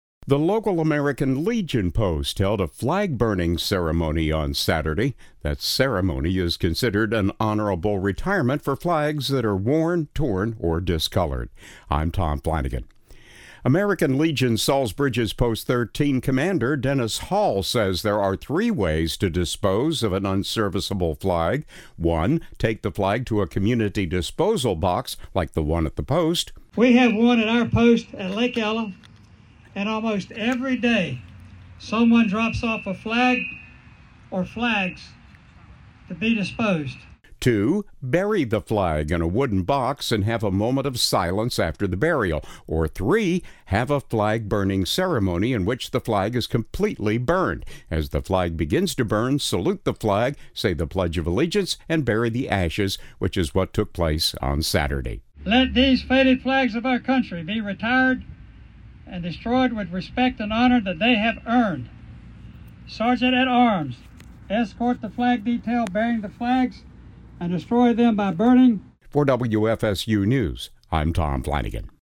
The ceremony is considered an honorable retirement for flags that are worn, torn or discolored.